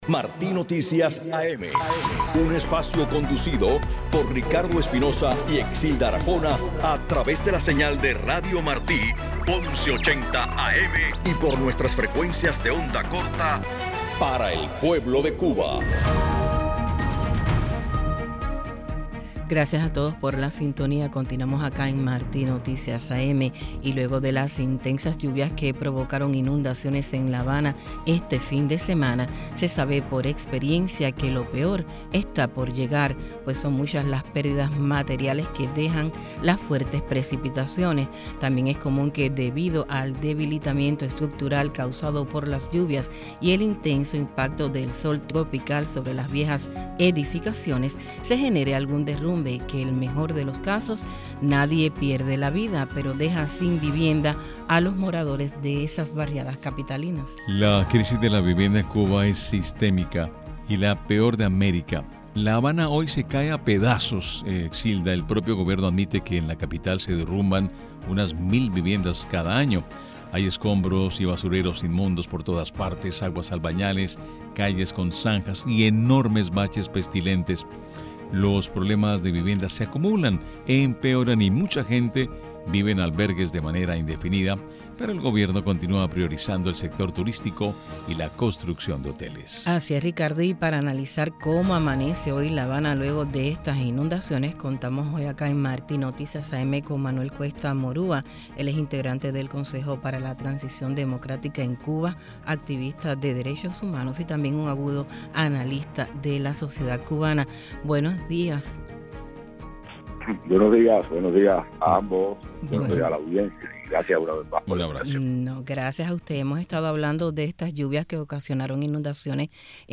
Declaraciones del activista Manuel Cuesta Morúa al informativo Martí Noticias AM